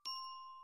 3. Xylophone, (sounds)